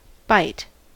byte: Wikimedia Commons US English Pronunciations
En-us-byte.WAV